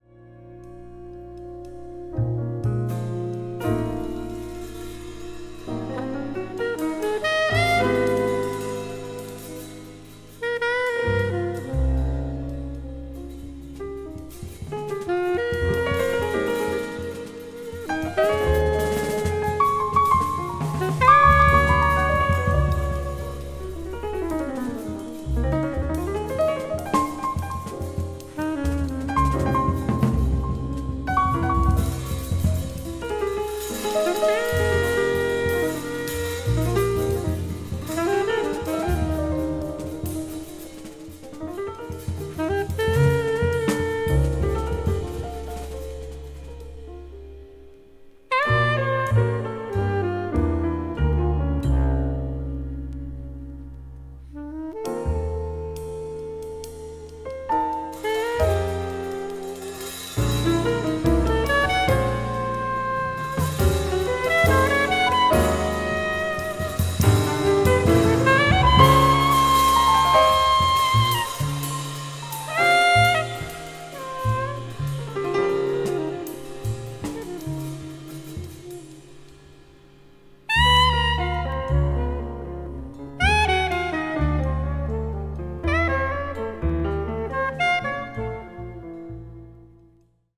alto and soprano sax
piano
bass
drums and percussion